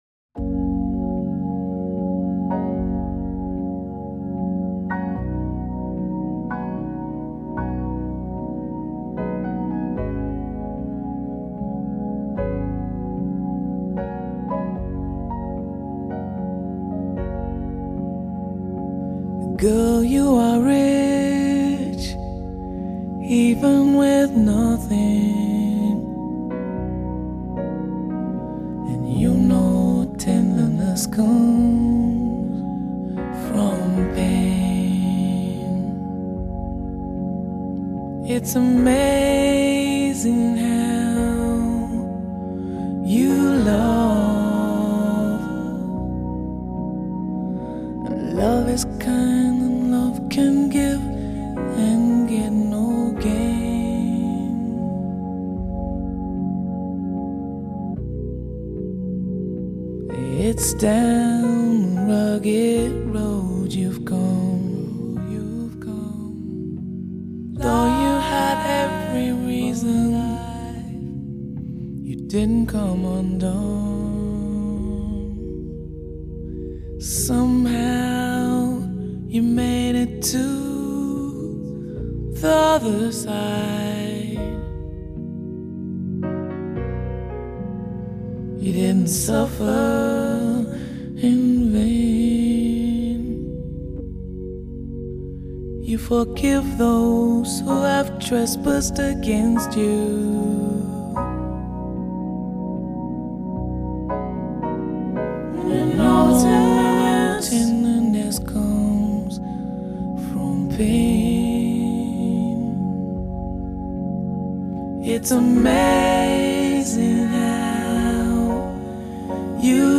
爵士，流行，R&B,再加上一点Funk，像调
郁，带着些些的沙哑，初听之下几乎不能相信是一个女歌手的歌声，